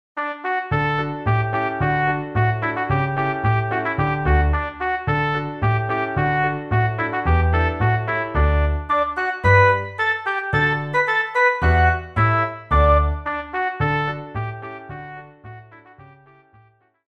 RÉPERTOIRE  ENFANTS